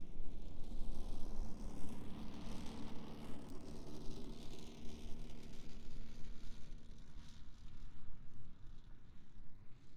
Zero Emission Subjective Noise Event Audio File - Run 1 (WAV)
Zero Emission Snowmobile Description Form (PDF)